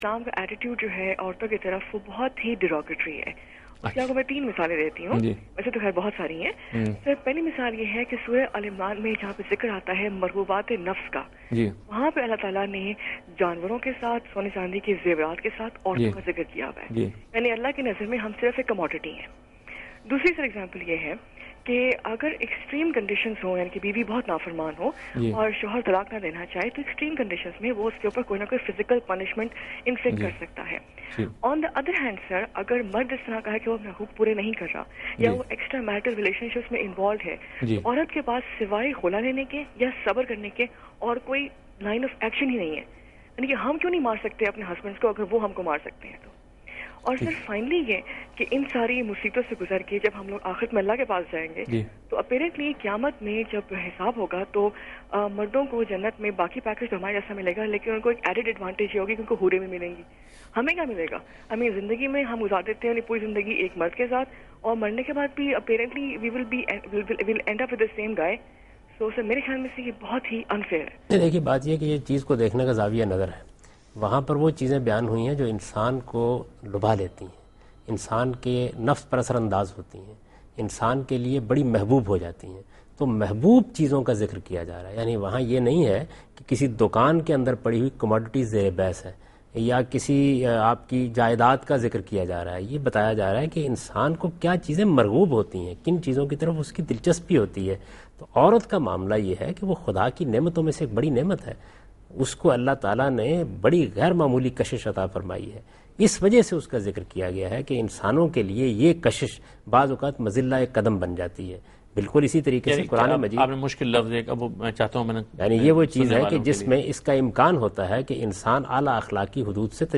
Answer to a Question by Javed Ahmad Ghamidi during a talk show "Deen o Danish" on Dunya News TV